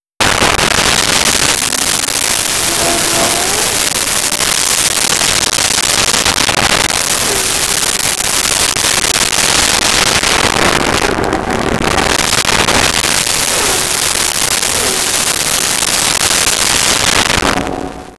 Top Fuel Car Fast By Multiple
Top Fuel Car Fast By Multiple.wav